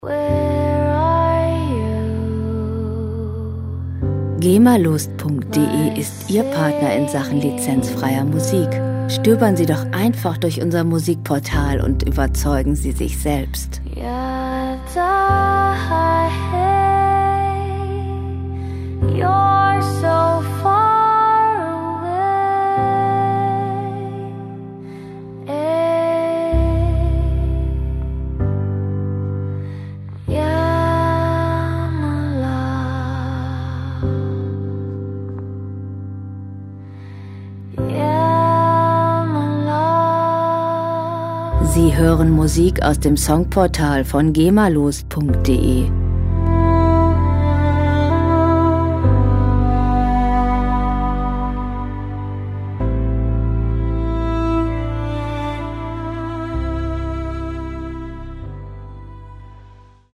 • Ethereal Wave